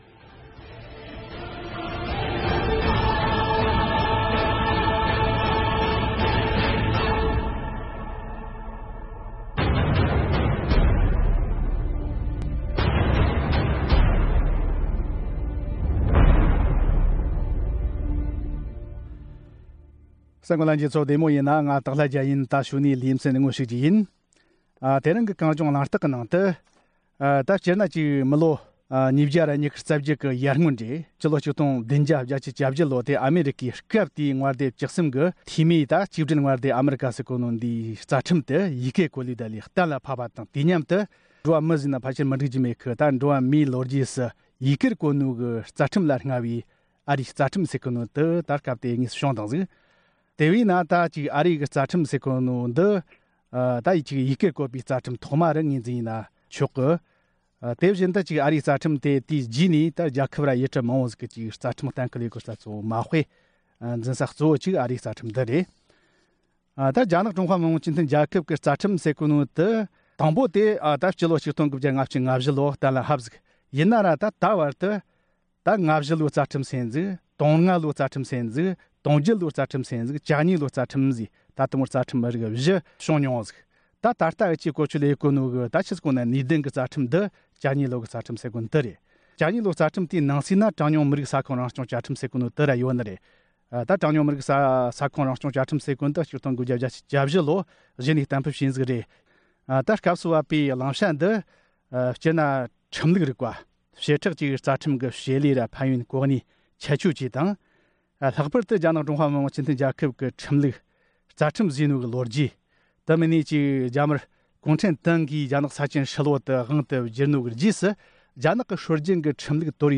ལྷན་བགྲོ་གླེང་བྱེད་ཀྱི་ཡིན།